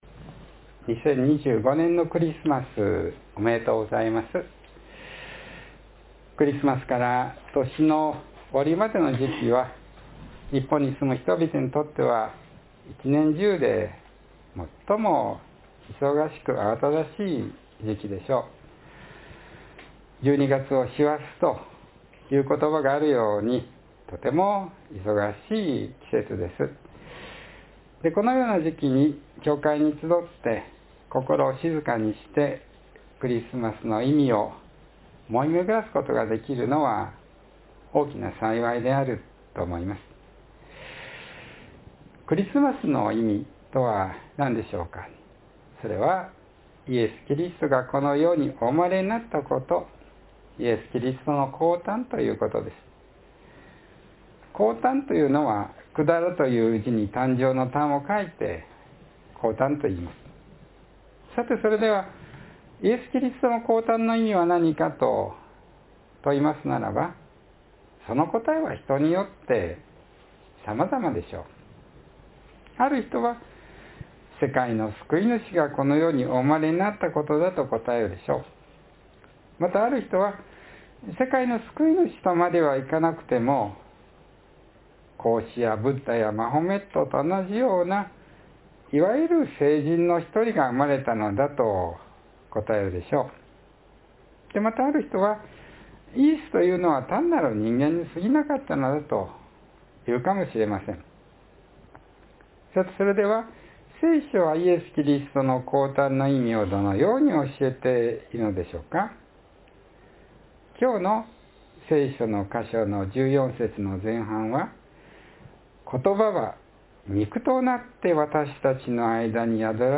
（12月21日の説教より）